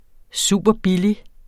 Udtale [ ˈsuˀbʌˈ- ]